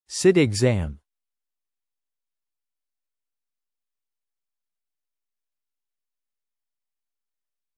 Spelling: /sɪt ɪɡˈzæm/